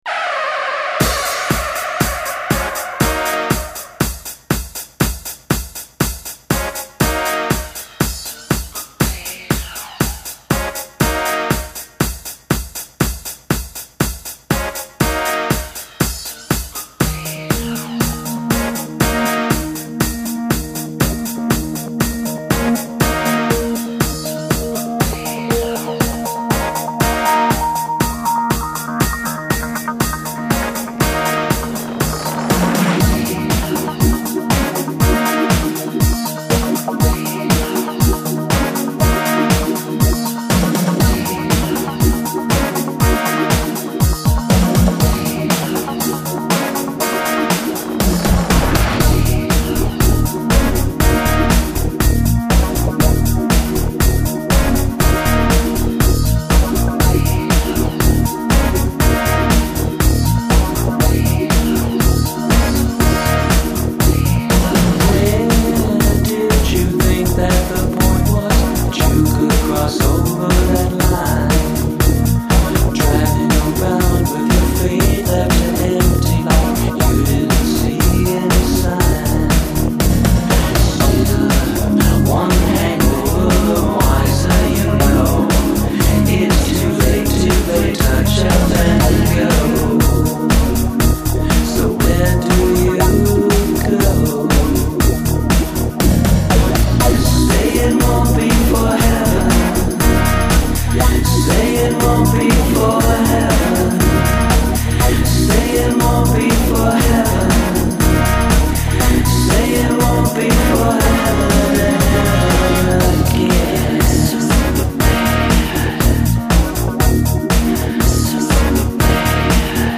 Recapturing AM radio in pop songs with lush arrangements.